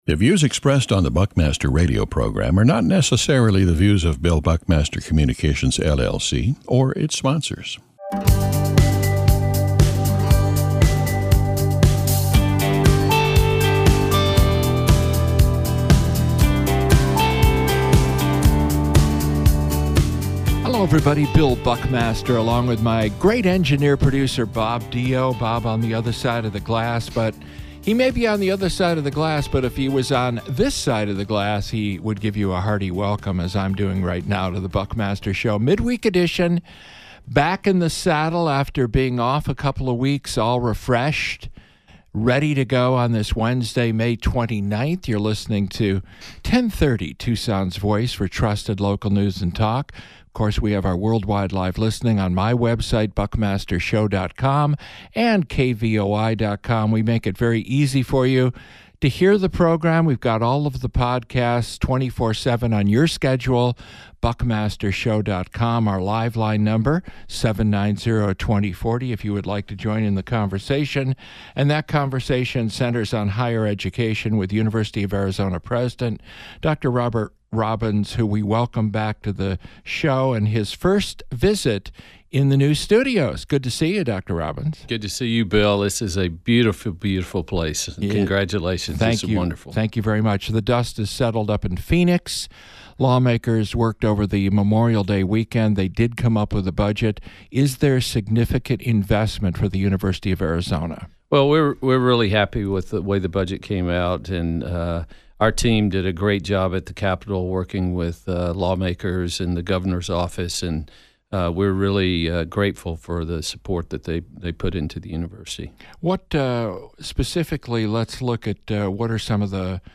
Our newsmaker interview